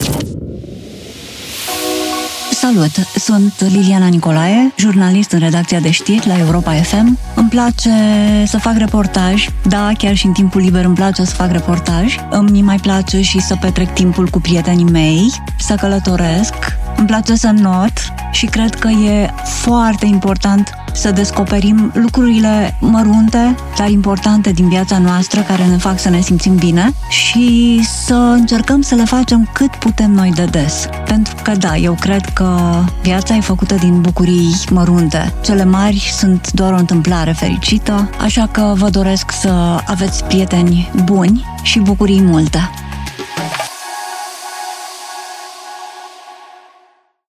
Câteva dintre mesajele colegelor noastre: